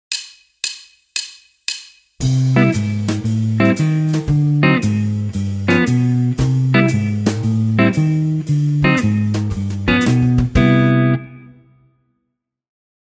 Этот блюзовый оборот не ограничен движениями между V и I ступенью.
К примеру, в тональности До-мажор мы могли бы использовать аккорды Cmaj7, Am7, Dm7, G7, но в нашем примере добавлено немного "джазовости" за счет басовой линии.